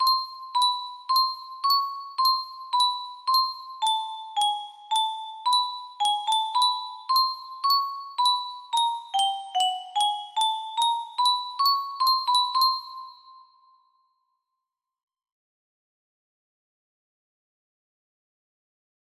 Music box tune
Grand Illusions 30 (F scale)